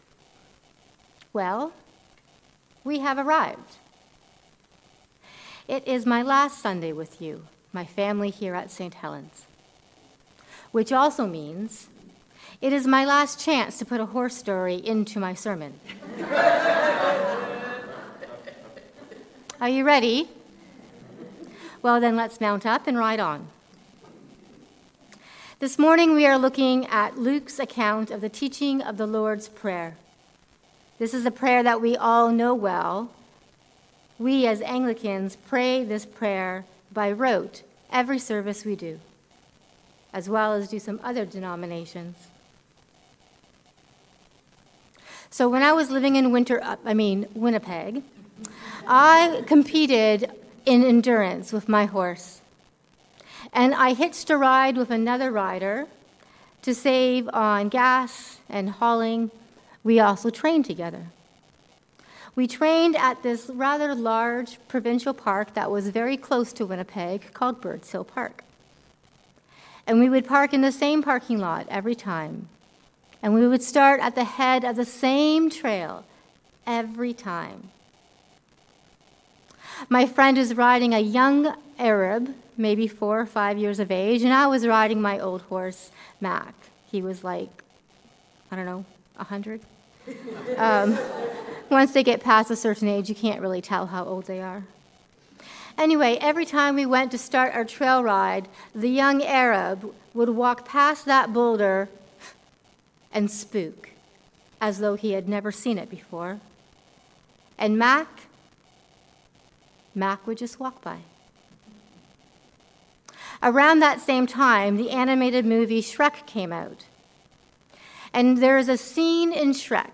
Encountering the Lord’s Prayer. A sermon on Luke 11:1-13